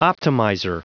Prononciation du mot optimizer en anglais (fichier audio)
Prononciation du mot : optimizer